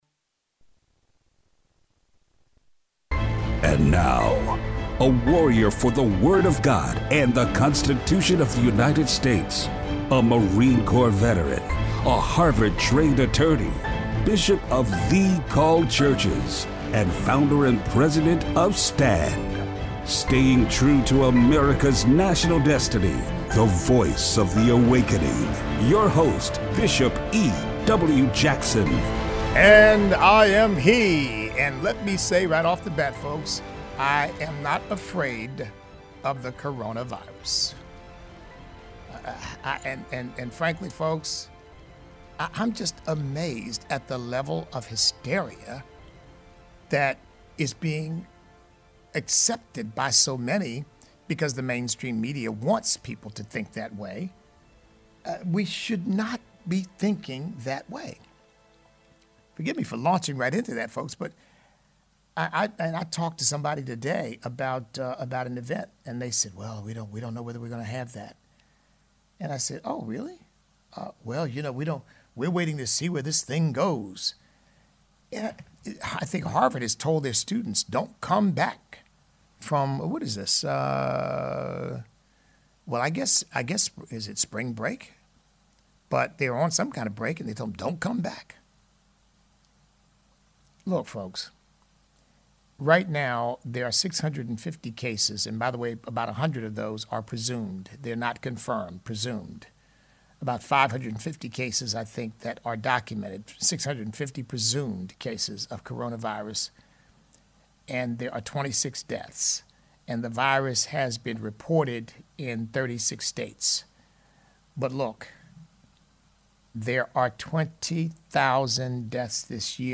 So what are the differences between communism, socialism and democratic socialism? Listener call-in.